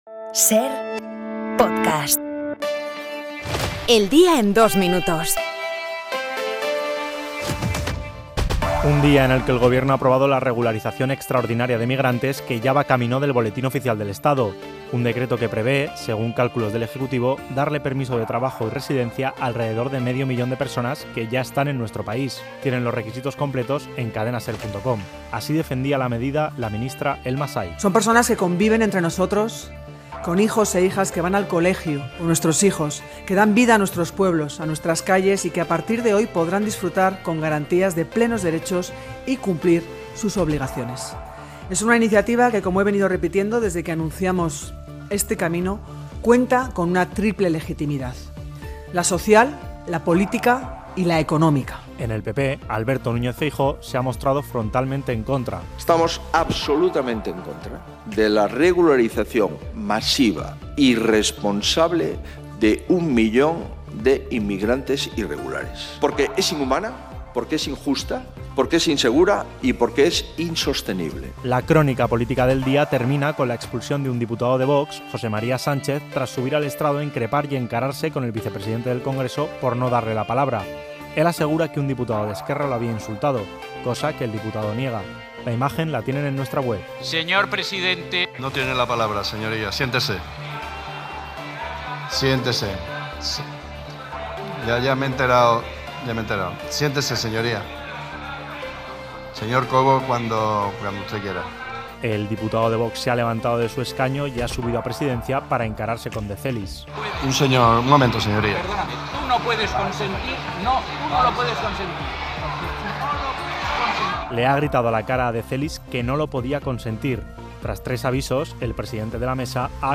El resumen de las noticias de hoy